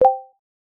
message_notification.X5pKkQqN.wav